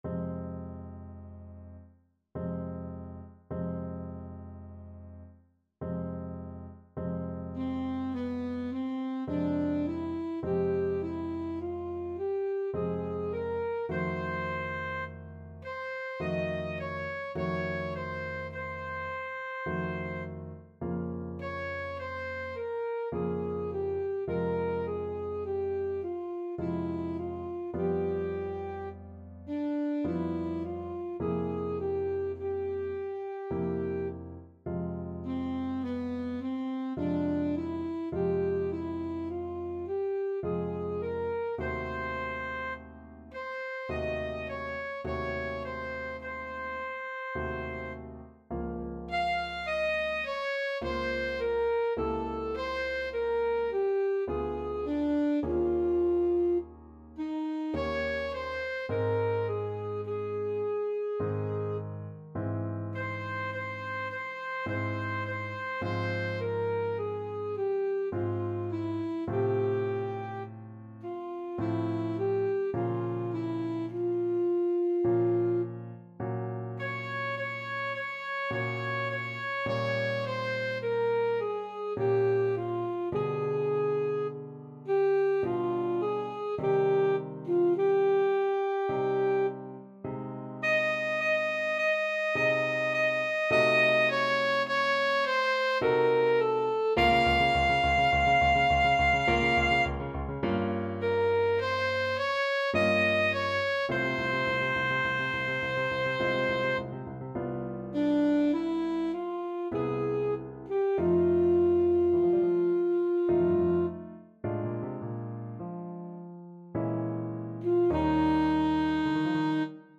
Alto Saxophone Classical
Score Key: F minor (Sounding Pitch) D minor (Alto Saxophone in Eb) ( View more F minor Music for Saxophone ) Tempo Marking: Andante molto moderato ( =66) ~ = 52 Duration: 2:21 Range: B4-F6 Time Signatur